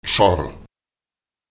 Lautsprecher jar [tSar] enthalten, beinhalten